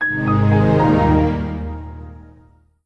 Windows NT 6.0 Embedded Shutdown.wav